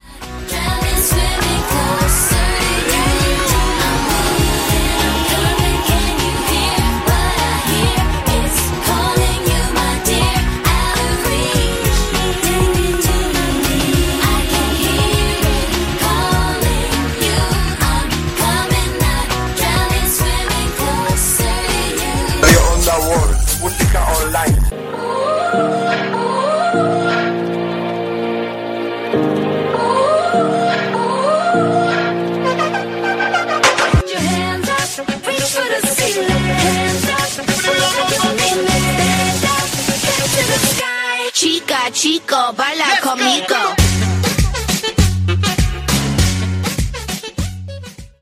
Tema musical, identificació de la ràdio i tema musical